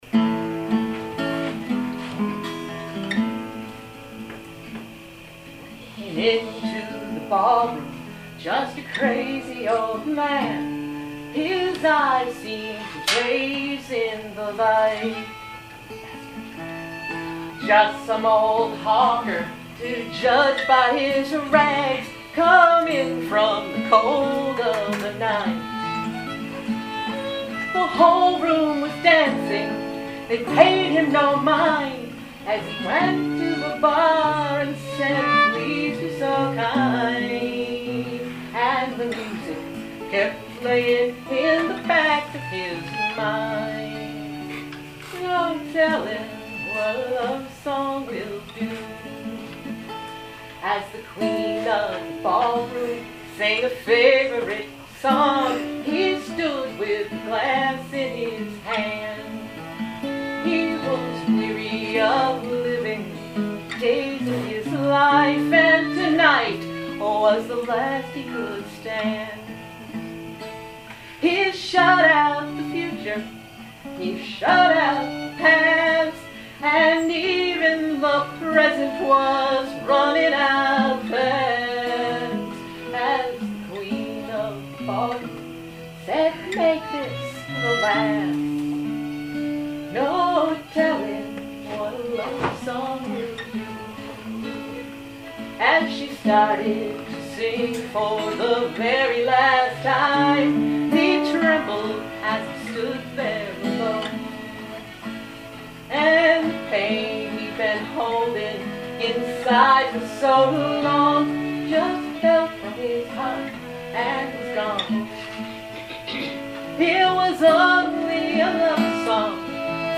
Though more than half the songes were recorded at the campers concert held at the end of the week, some were recorded at informal late night singing sessions.